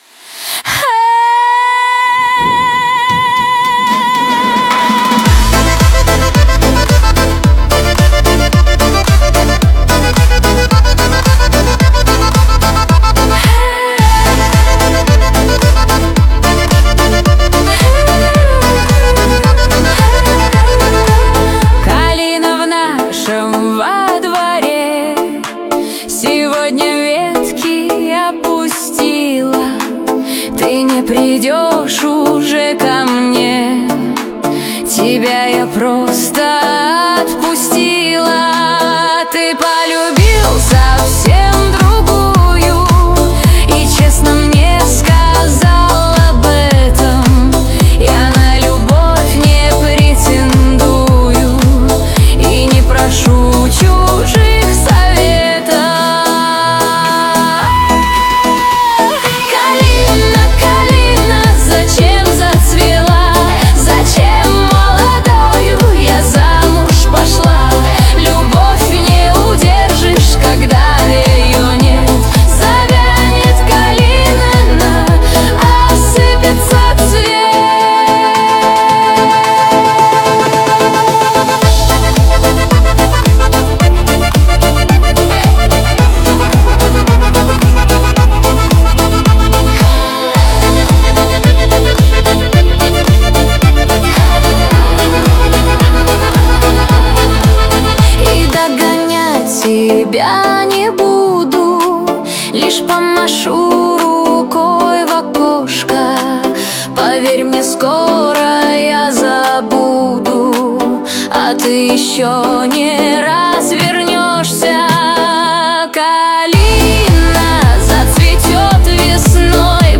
созданный с помощью искусственного интеллекта.